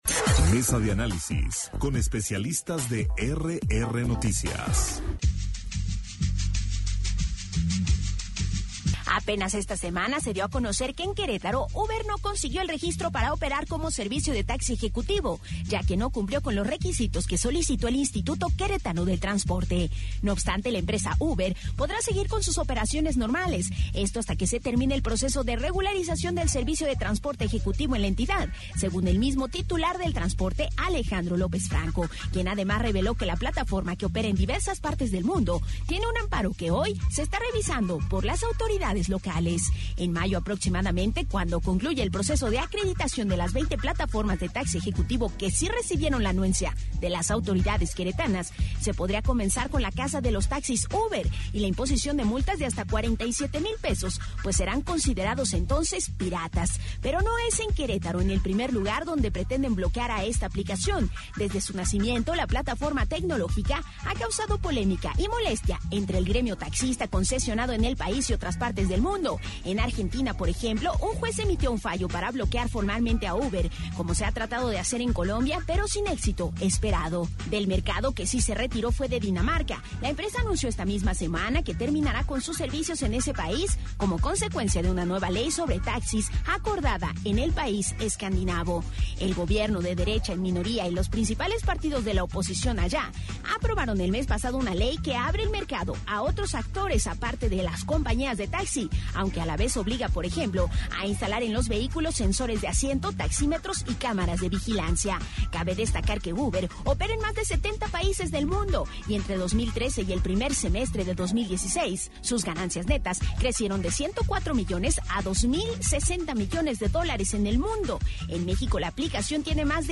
Mesa de Análisis en RR Noticias sobre el posible "Adiós a Uber" en Querétaro - RR Noticias